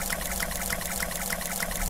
Pretty Blood Water Struggle Sound Button - Free Download & Play